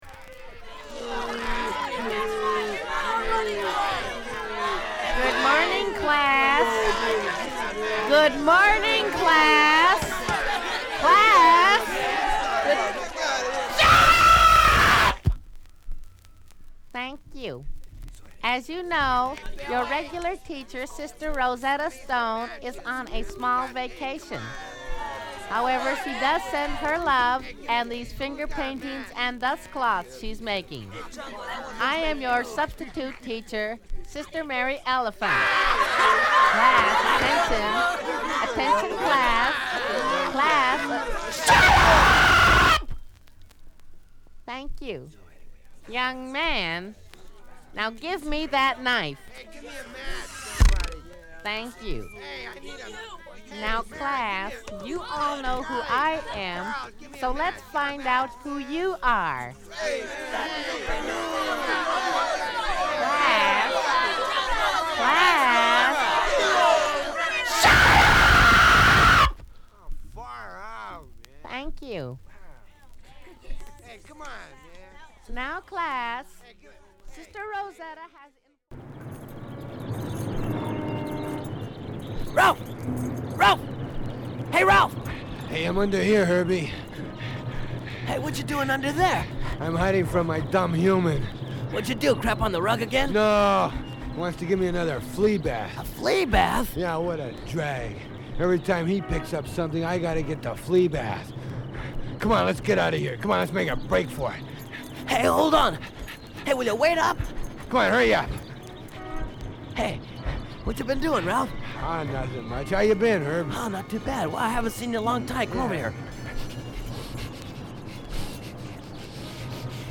老舗巻紙屋のBig Bambuをパロディしたジャケットでもお馴染みの本作の一曲目は教室ネタのA1「Sister Mary Elephant」、ストリートでの危ない日常会話が面白いA2「Ralph And Herbie」等、チーチョン節全開のお笑いトークを収録したコメディ作品！